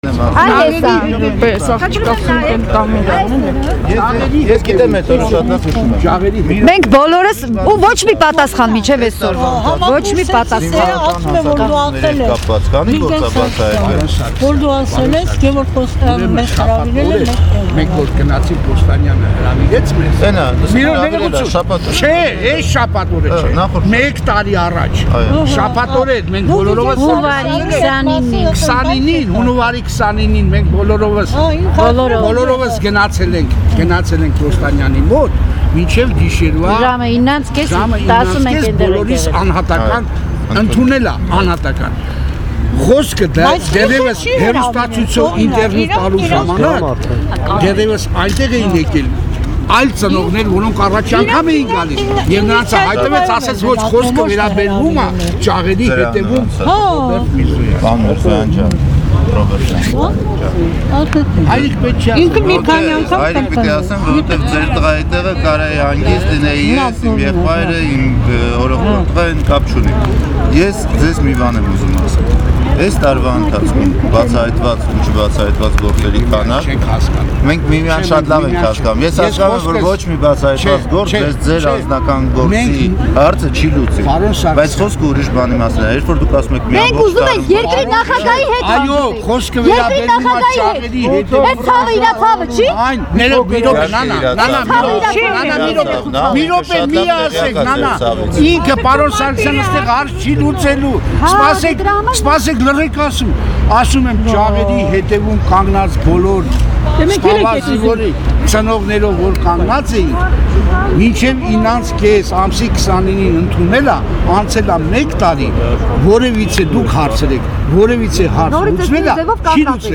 «Մեր ցավը նախագահի ցավը չի՞»,- այս հարցով այսօր նախագահի աշխատակազմի ղեկավար Վիգեն Սարգսյանին դիմեցին բանակում մահացած զինվորների ծնողները:
Կառավարության շենքի բակում նրանք Վ. Սարգսյանից խնդրում էին հանդիպում կազմակերպել նախագահի հետ.